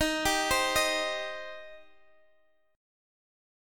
Eb6 Chord
Listen to Eb6 strummed